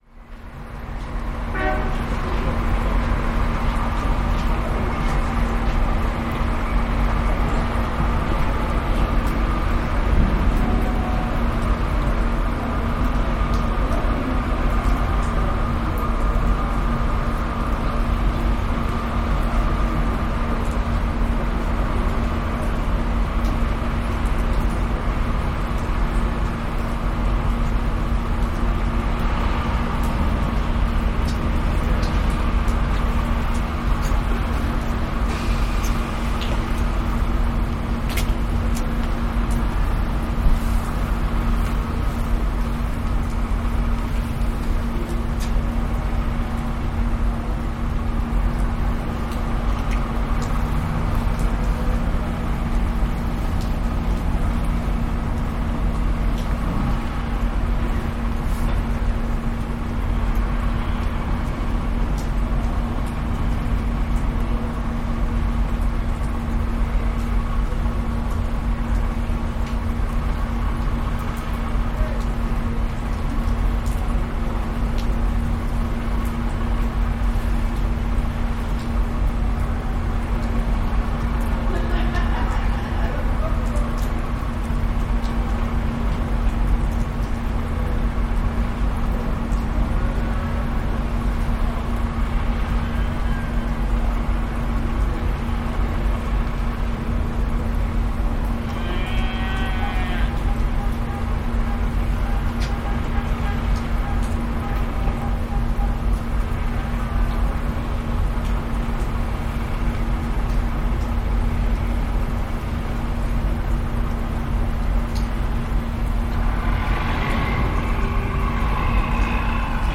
On board a docked ferry, Puerto Natales, Chile
Field recording taken on board the Navimag Evangelistas ferry while docked at Puerto Natales, Chile.